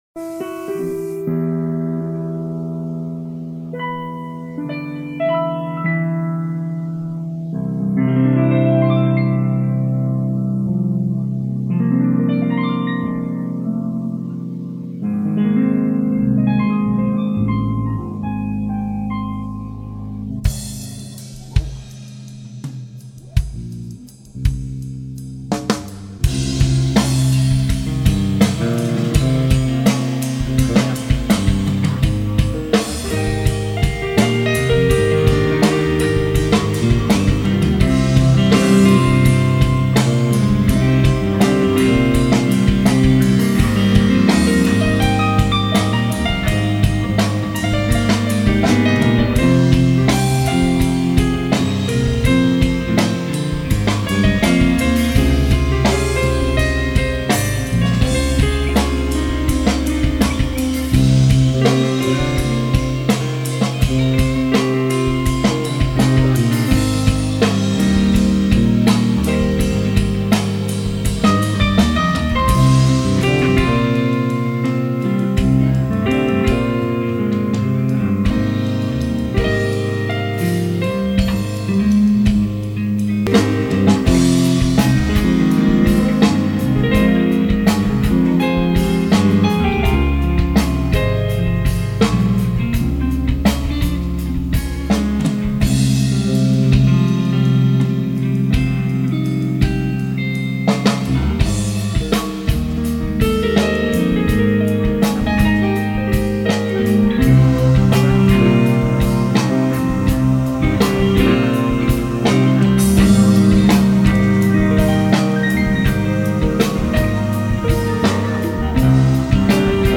Ein Stück aus der Kellerjazz-Zeit, eine ausgejammte Idee mehr inspiriert als auskomponiert.
Piano
Alto Sax
Casual Guitar
Bass, add.Keys